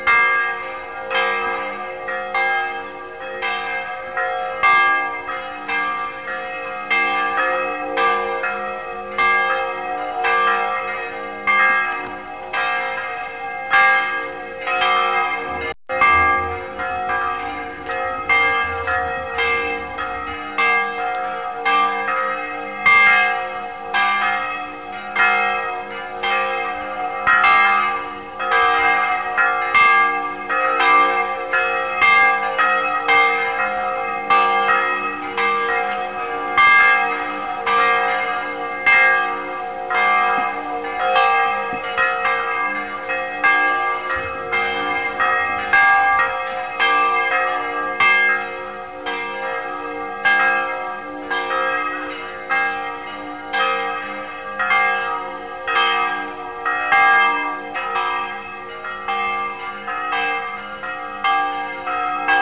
carillon_test.wav